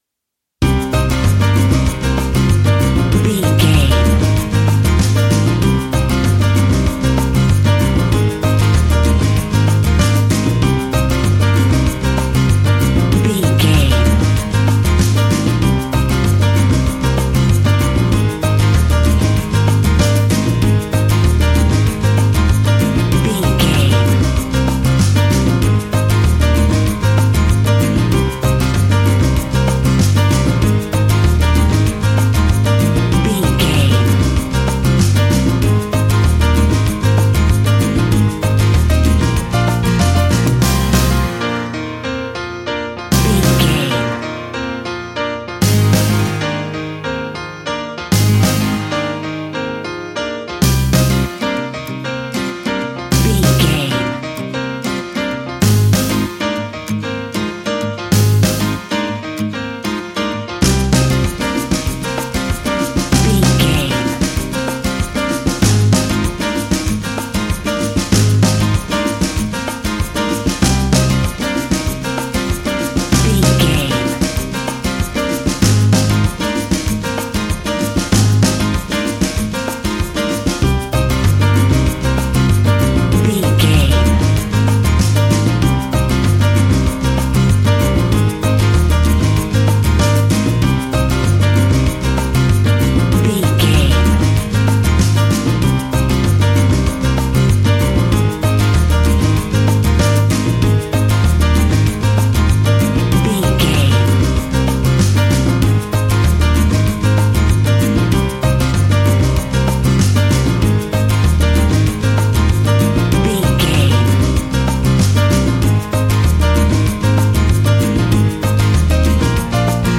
An exotic and colorful piece of Espanic and Latin music.
Aeolian/Minor
maracas
percussion spanish guitar